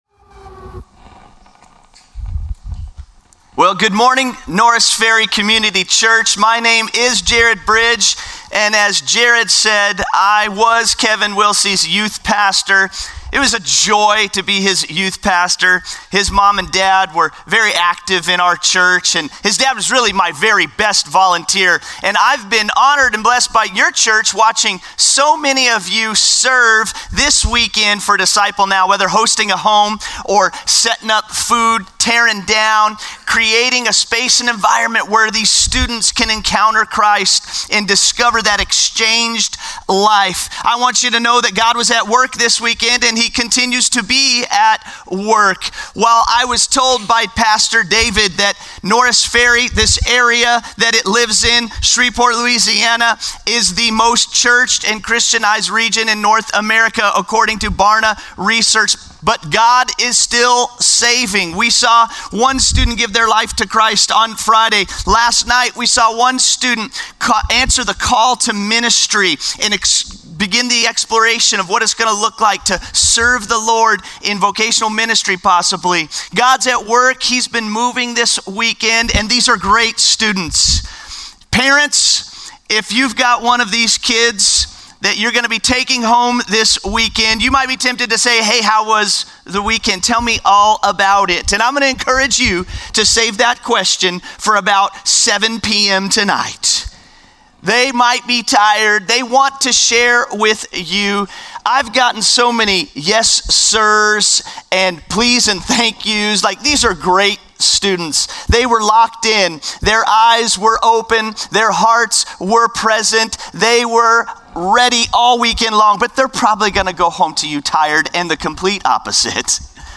Norris Ferry Sermons Feb. 9, 2025 -- DNOW 2025 -- 2 Corinthians 5:16-21 Feb 09 2025 | 00:36:12 1x 00:00 / 00:36:12 Subscribe Share Spotify RSS Feed Share Link Embed